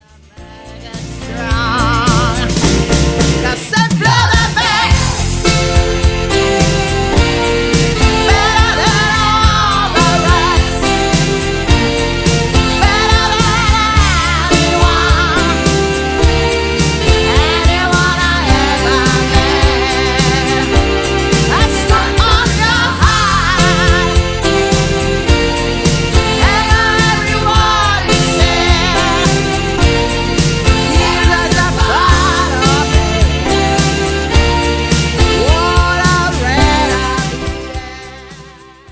tritt in der Regel als Duo mit Live-Keyboard auf